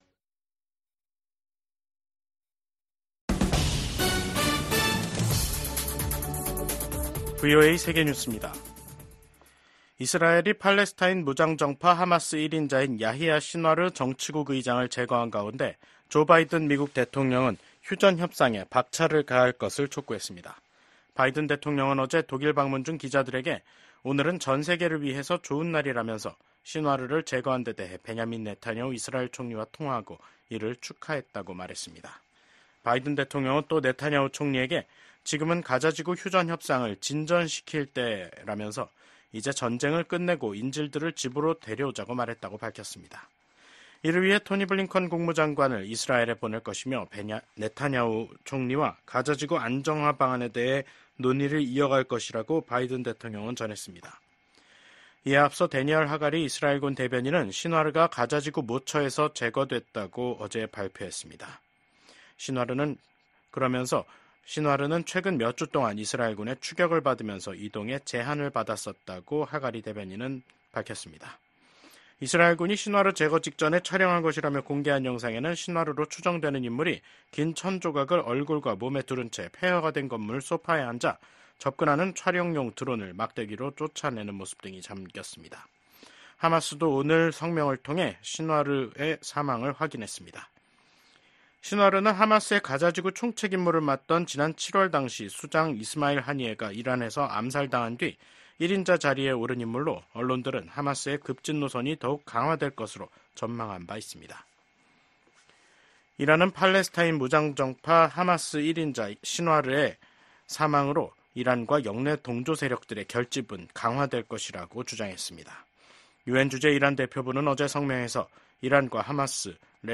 VOA 한국어 간판 뉴스 프로그램 '뉴스 투데이', 2024년 10월 18일 3부 방송입니다. 북한이 한국을 헌법상 적대국으로 규정한 가운데 김정은 국무위원장은 전방부대를 방문해 한국을 위협하는 행보를 보였습니다. 윤석열 한국 대통령은 국가안보실, 국방부, 국가정보원 핵심 관계자 등이 참석한 가운데 ‘북한 전투병의 러시아 파병에 따른 긴급 안보회의’를 열어 대응 방안을 논의했다고 대통령실이 전했습니다.